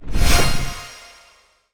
magic_flame_of_light_04.wav